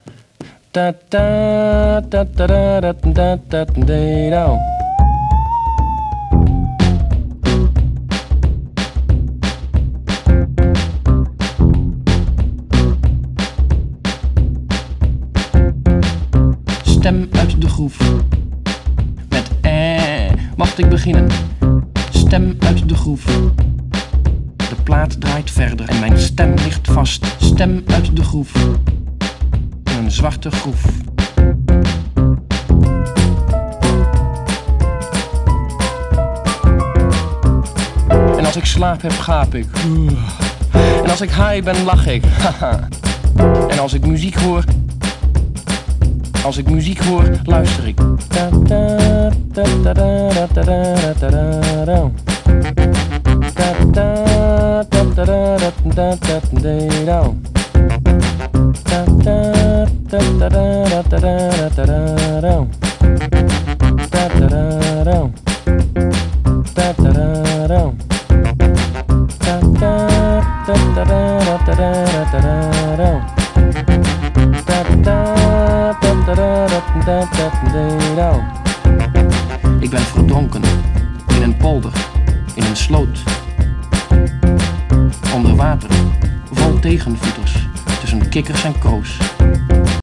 one-man band